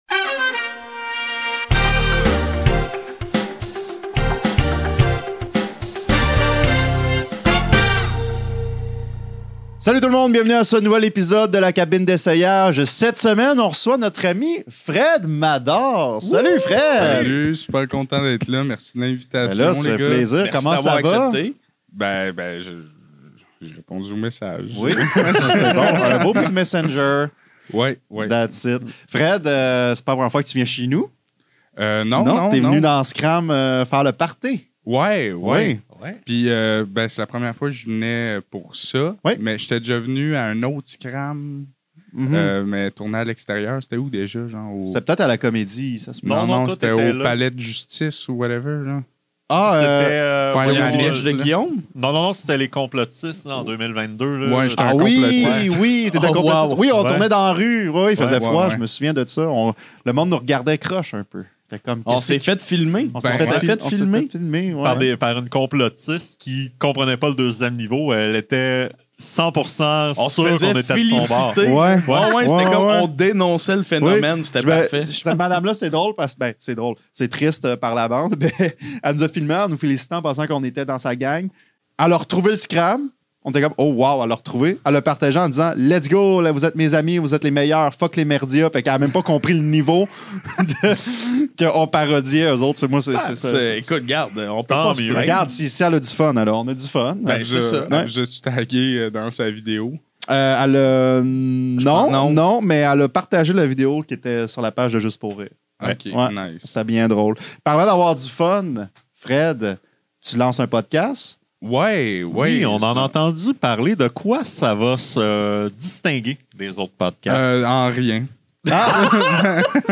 La Cabine d’Essayage est un podcast qui met l’emphase sur la création et l’improvisation. À chaque épisode, Les Piles-Poils et un artiste invité doivent présenter un court numéro sous forme de personnage (ou de stand-up) à partir d’un thème pigé au hasard.